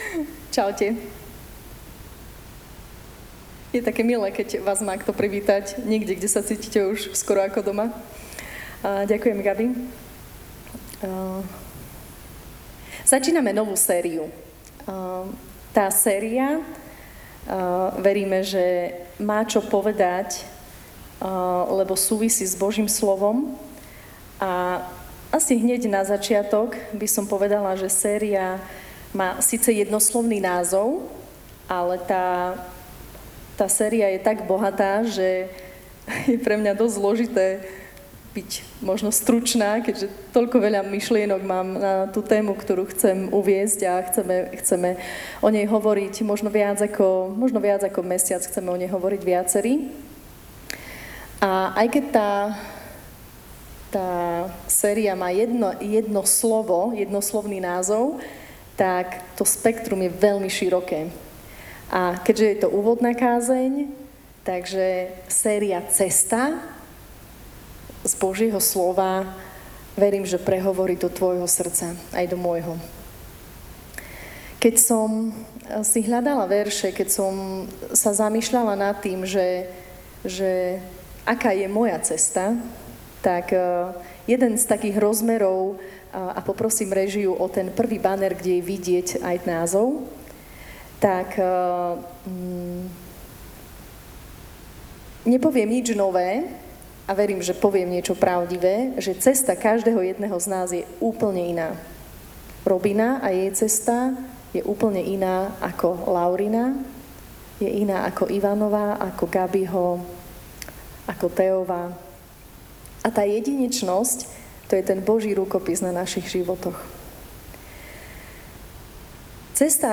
Audio kázeň